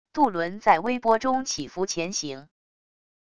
渡轮在微波中起伏前行wav音频